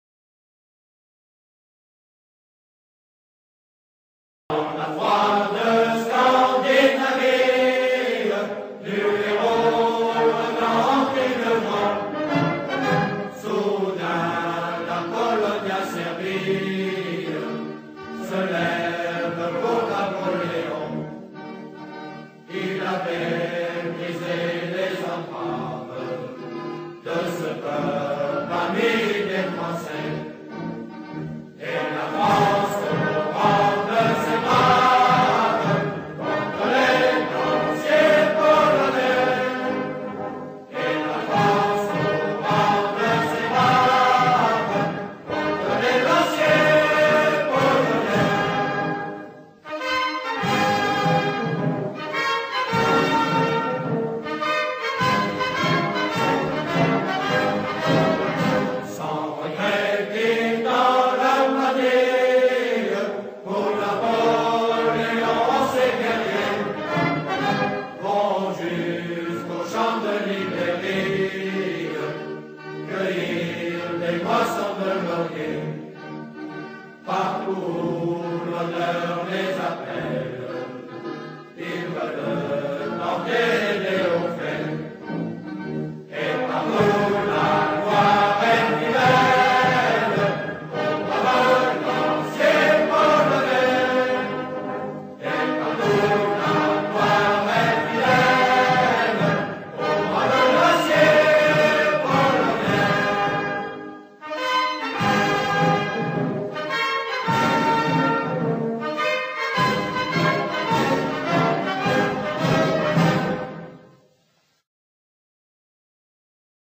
Les-Lanciers-Polonais-chant-militaire-de-1814.mp3